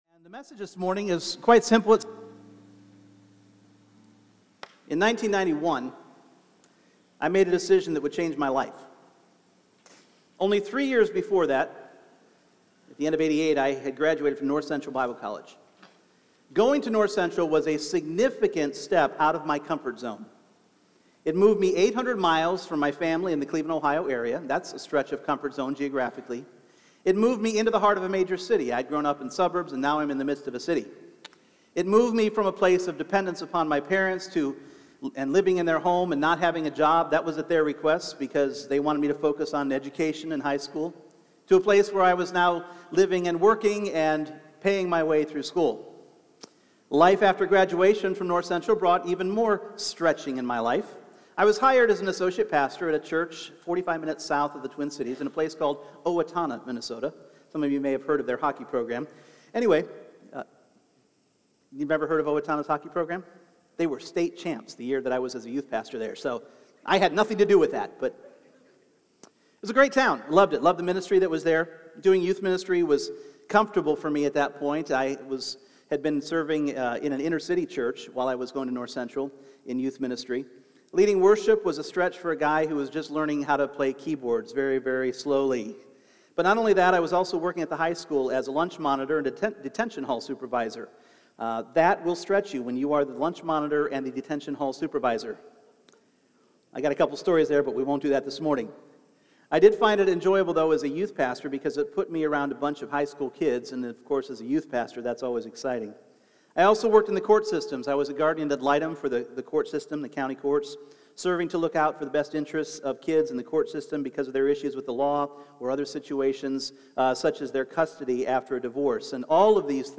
Individual Messages Service Type: Sunday Morning What does living in a foreign country have to do with faith?